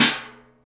PRC STICK0MR.wav